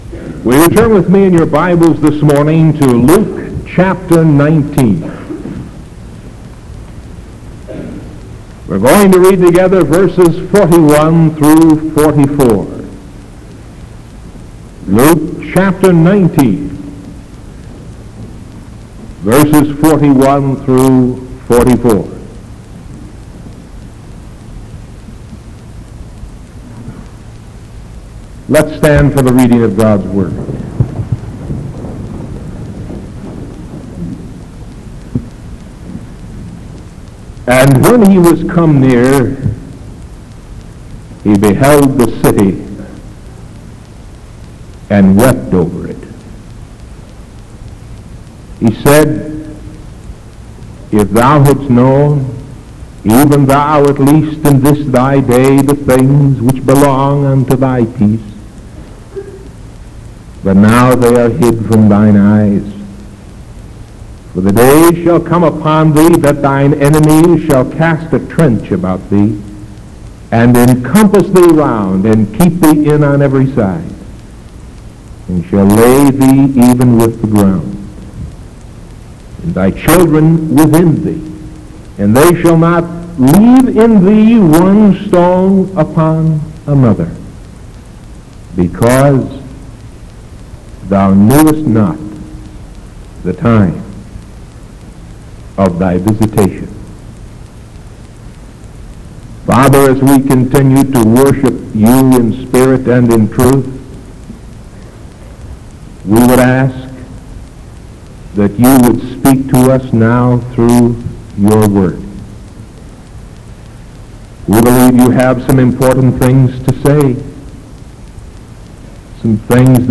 Sermon September 2nd 1973 PM